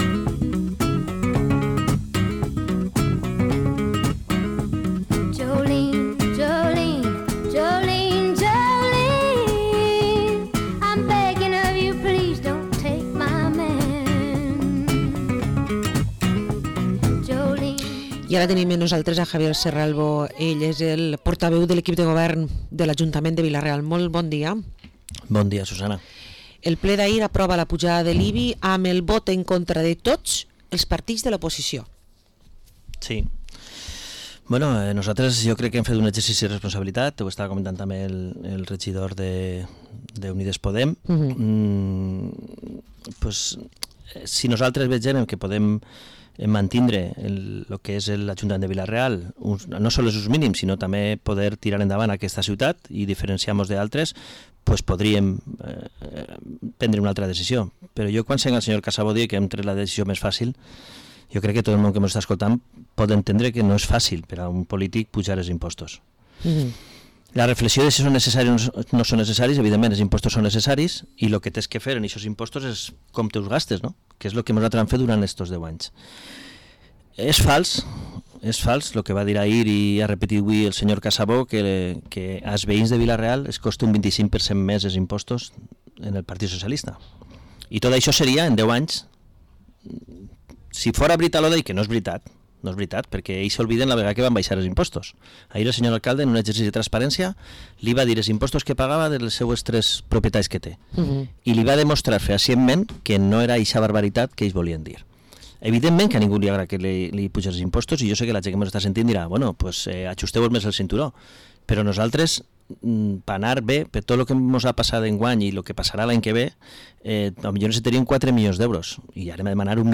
Entrevista al portavoz del equipo de gobierno de Vila-real, Javier Serralvo